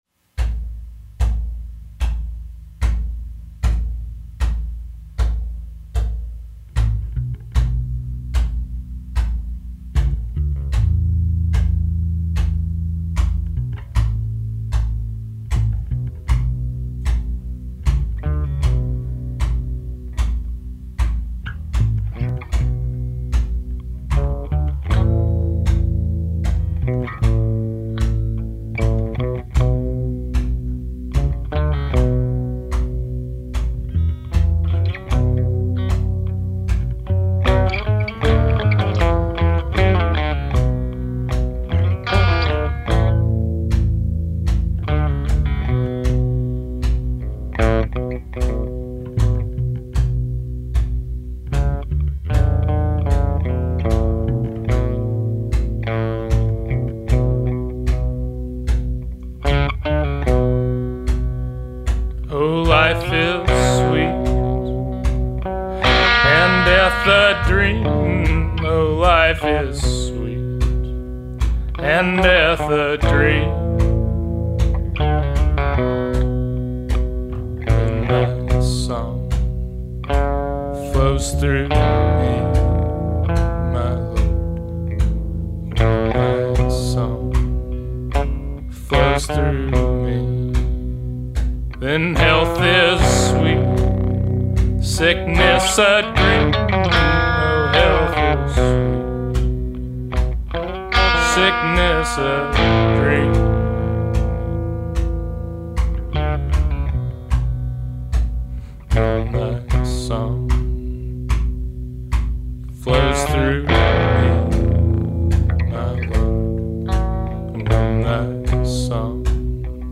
booming voice
punk-rock acoustic threats